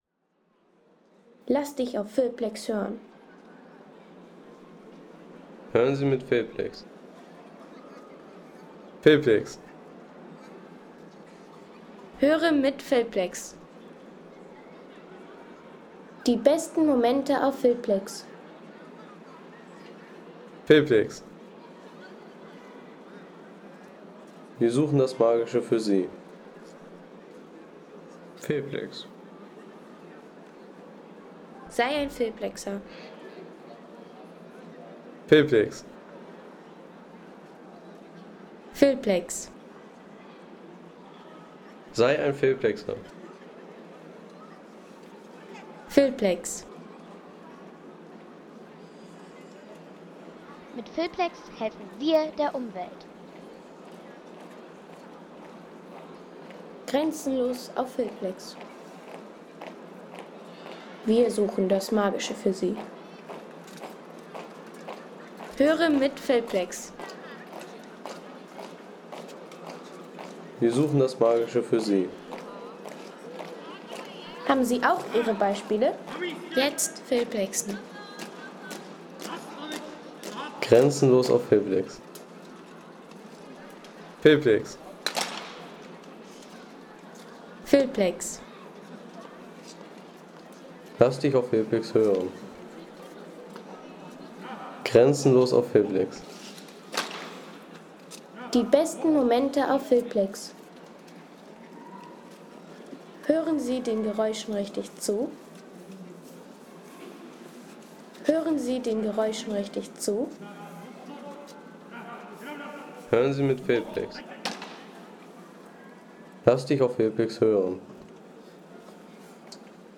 Erholungsgenuss für alle, wie z.B. diese Aufnahme: Feierliche Wachablösung an der Prager Burg
Feierliche Wachablösung an der Prager Burg